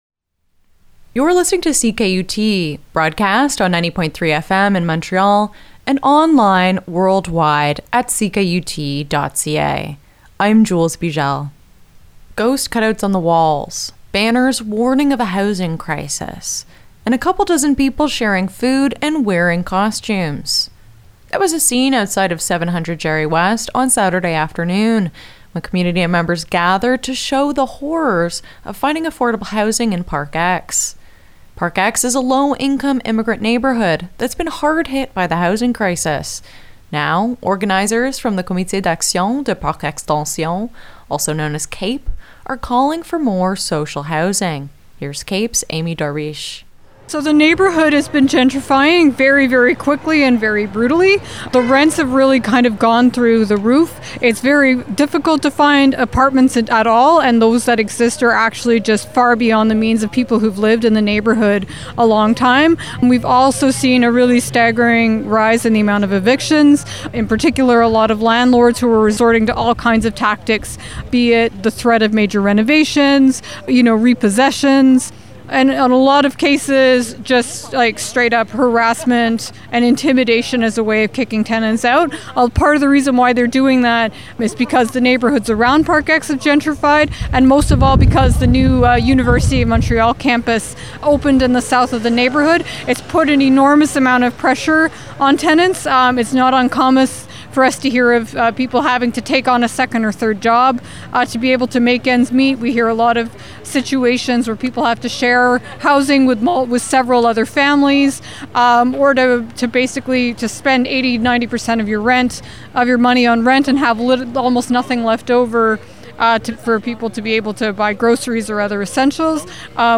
A couple dozen people rallied outside of 700 Jarry West on Saturday afternoon to protest the high price of housing in Montreal's Parc Extension neighbourhood.
The mood was festive, with organizers handing out samosas.
Parc-Ex-social-housing-rally-10-30.mp3